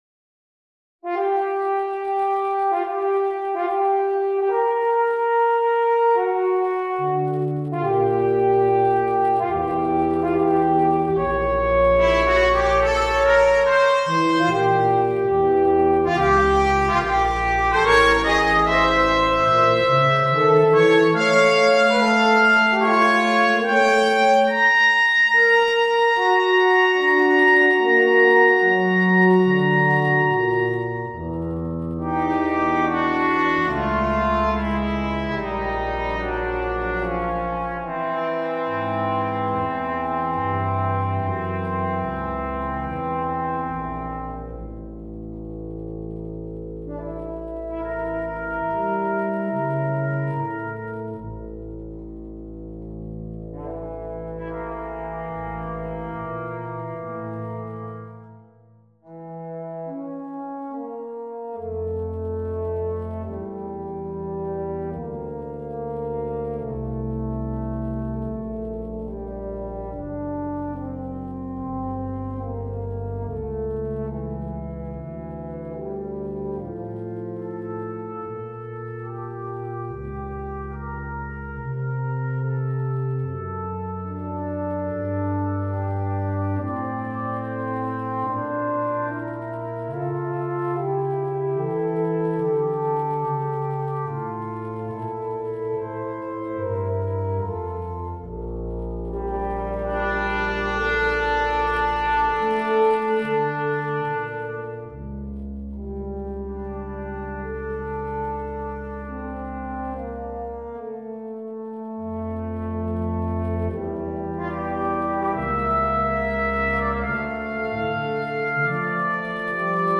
トリオ
Part 2: F Horn
Part 2: Eb Horn
Part 3: Tuba – Bass clef